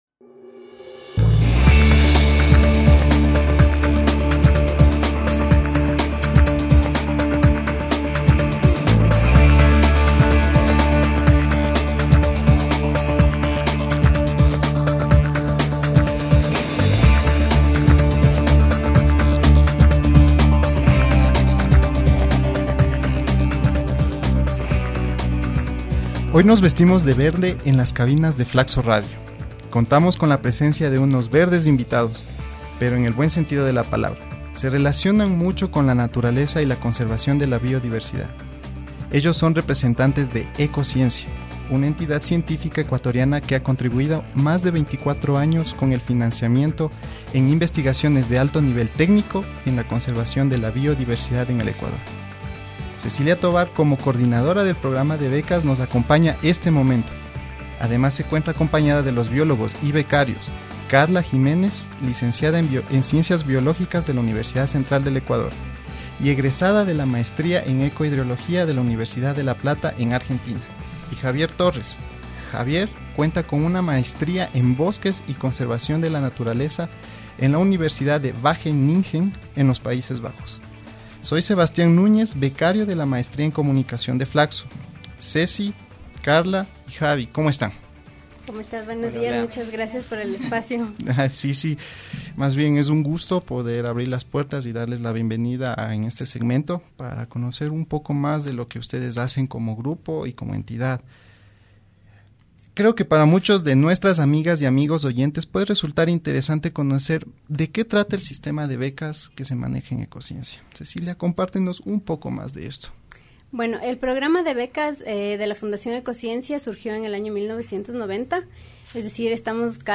Les invitamos a escuchar la entrevista y ha reflexionar un poco más sobre nuestra responsabilidad con el medio ambiente.